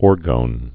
(ôrgōn)